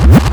REVERSBRK2-R.wav